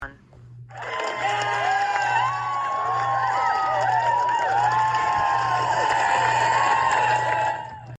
sfx_crowd_cheer.mp3